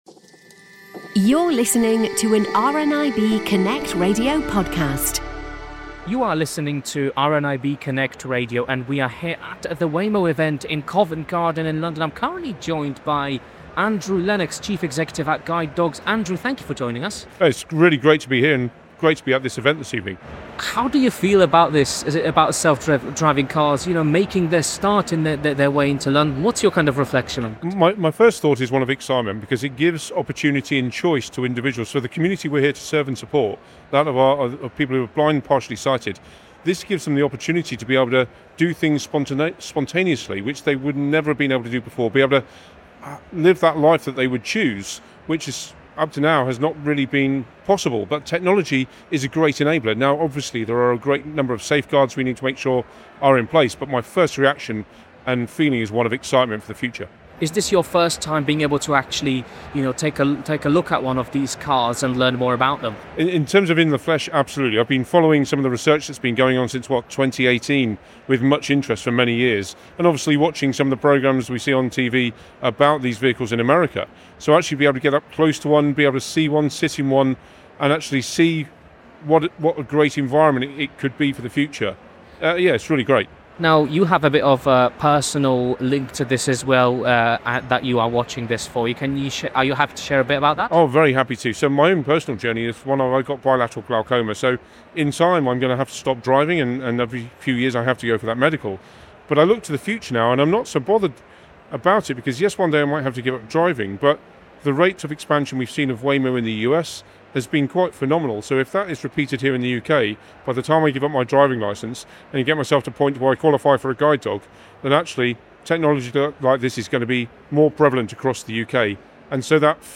went along to the launch event to find out more.